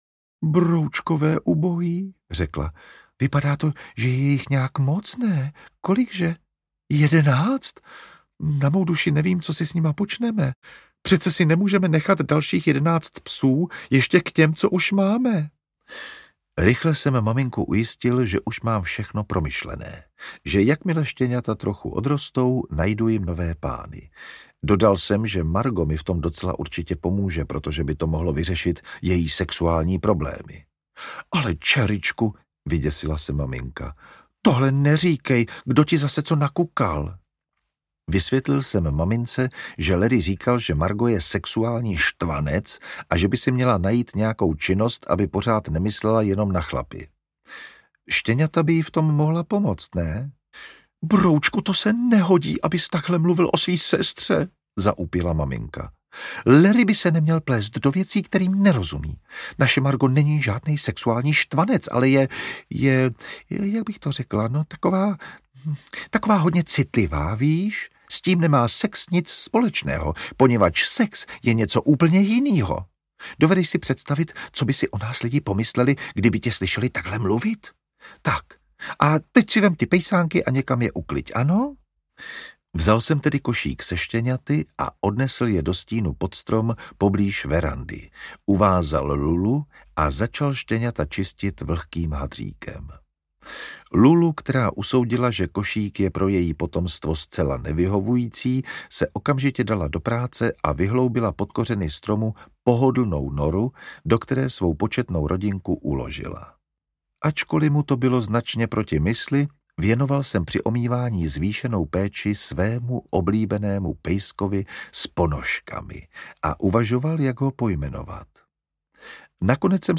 Zahrada bohů audiokniha
Ukázka z knihy
• InterpretJan Vondráček